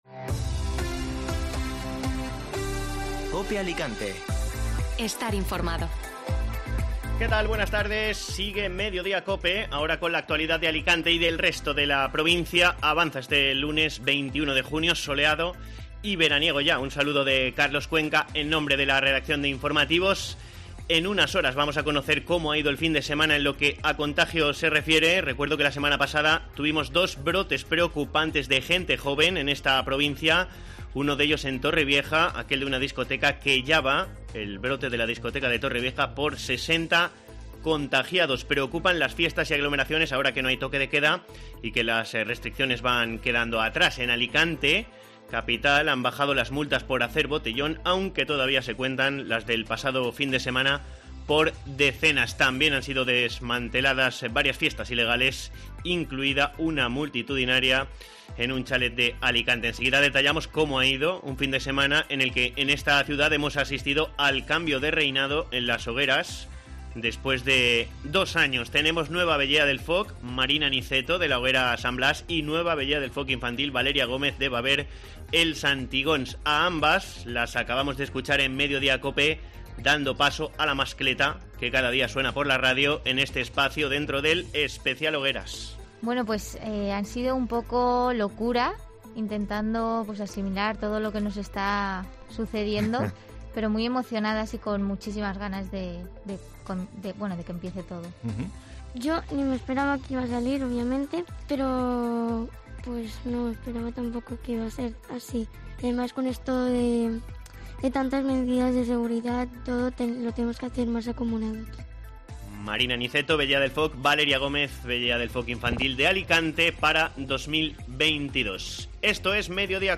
Alicante - Novelda Informativo Mediodía COPE (Lunes 21 de junio) Escucha las noticias de este lunes en Alicante. Descienden las multas por hacer botellón, el nuevo foco de contagios de coronavirus.